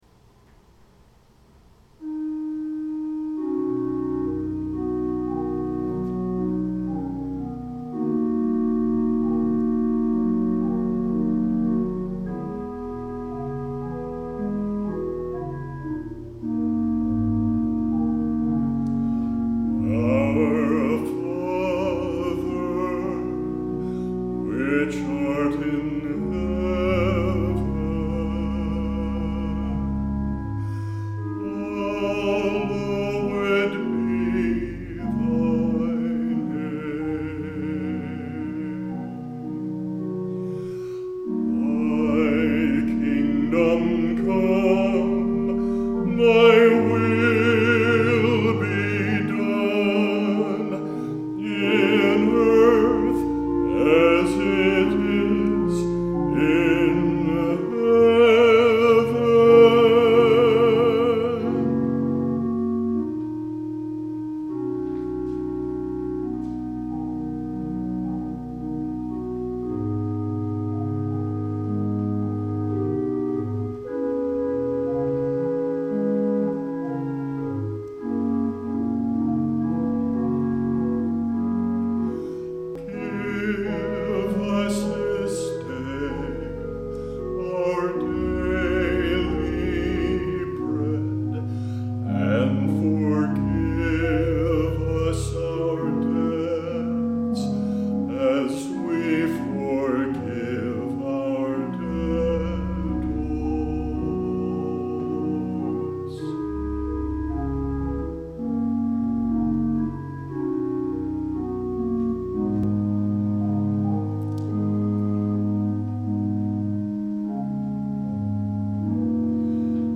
Latest Solos
The Lord's Prayer (2nd) - Carey 2/23/25 Grass Valley